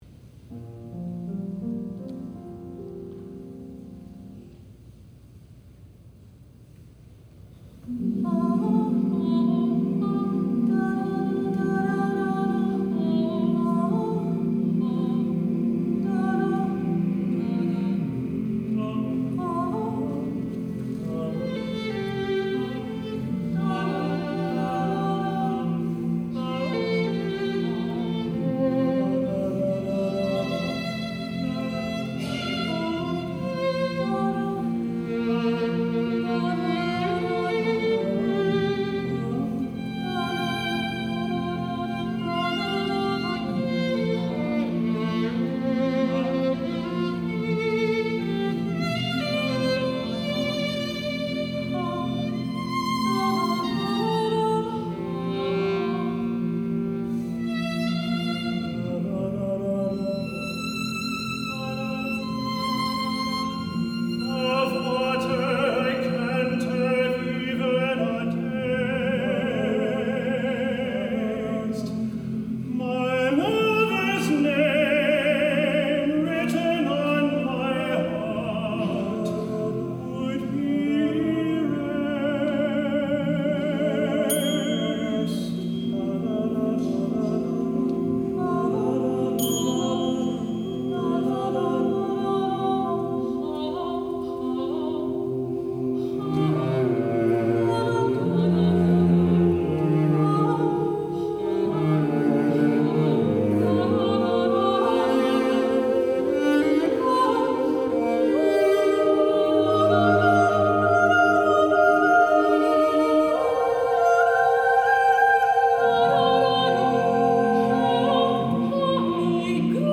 soprano & tenor duet, SATB, violin, cello, percussion